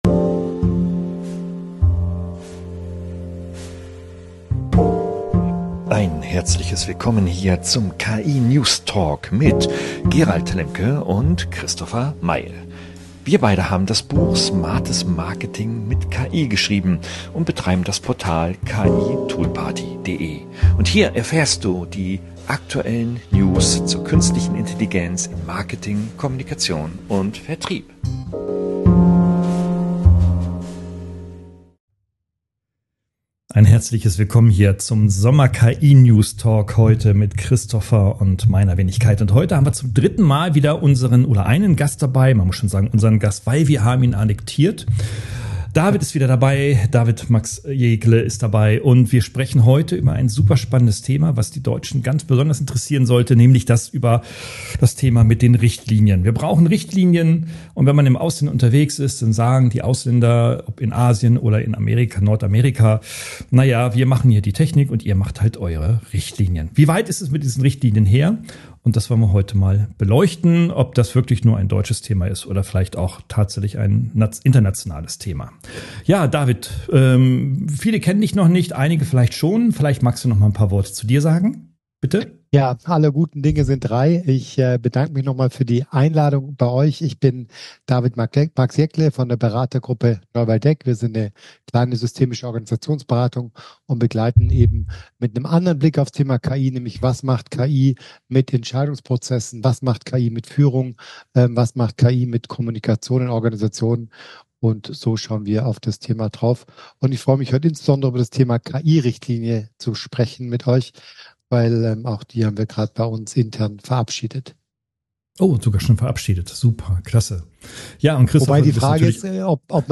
Nebenbei geht’s humorvoll und kritisch um moralische Fragen, KI im Alltag, schräge Erlebnisse mit Chatbots, neue Tools für Musik- und Sprach-KI – und darum, wie Unternehmen KI so einbinden können, dass es wirklich Mehrwert bringt. Ein ehrlicher, praxisnaher Talk voller Ideen, wie KI-Richtlinien nicht nur auf Papier existieren, sondern im Unternehmen tatsächlich gelebt werden.